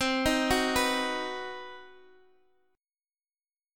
CmM7b5 chord